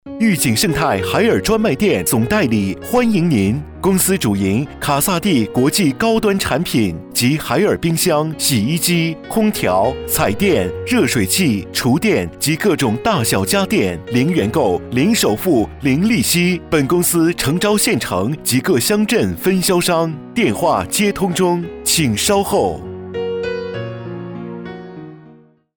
男38号配音师 点击进入配音演员介绍 进入后下滑播放作品集↓↓↓ 配音演员自我介绍 A级配音师，从事电台播音、主持工作12年 执着于播音创作，专业从事新闻、广告、专题解说等配音工作。
代表作品 Nice voices 彩铃 促销 专题片 彩铃-男38-海尔专卖店.mp3 复制链接 下载